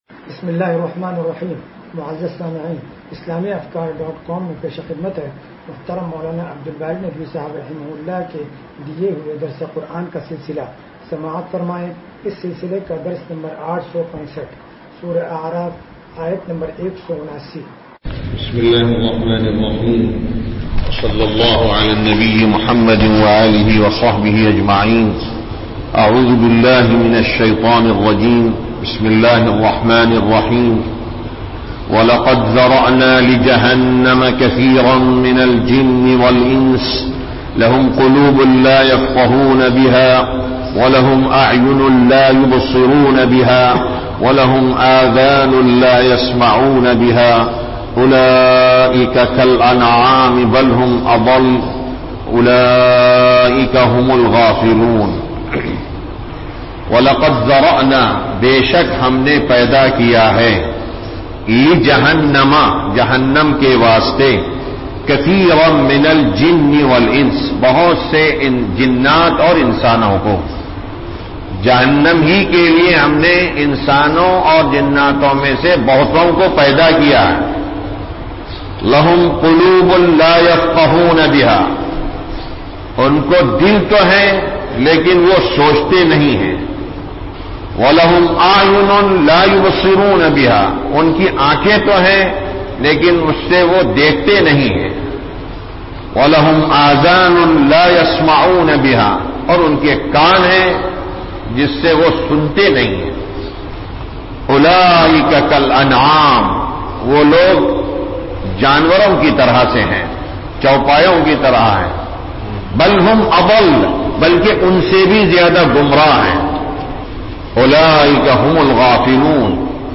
درس قرآن نمبر 0865
درس-قرآن-نمبر-0865.mp3